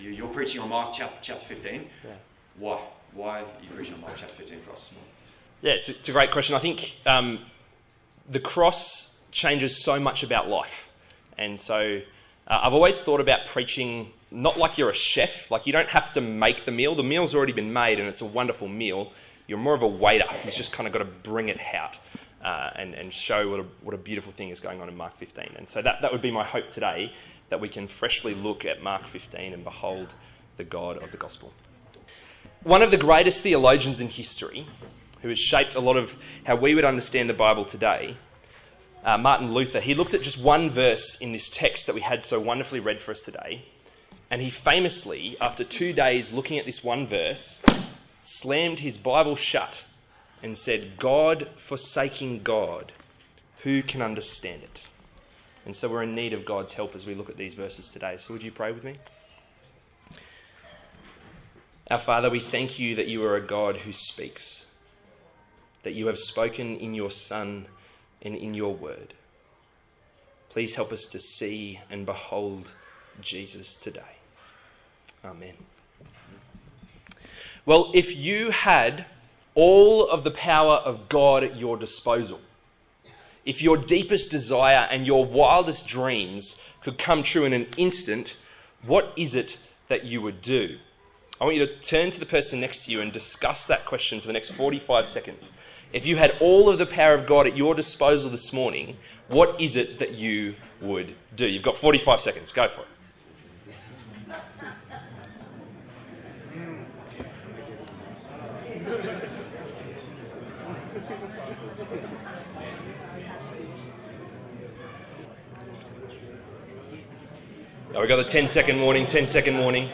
Mark Passage: Mark 15:25-39 Service Type: Sunday Morning